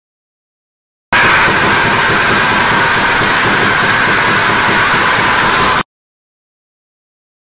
Monolith Death Sound Da Battle Bricks